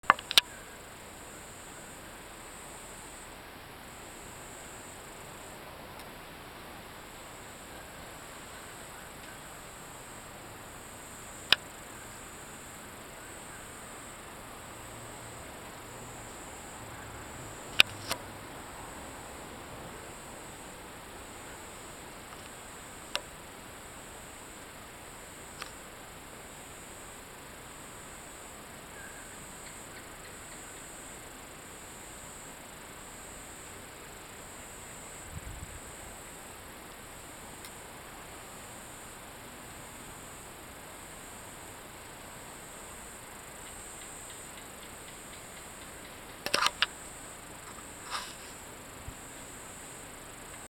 Flying Foxes Waking Up And Going For Some Breakfast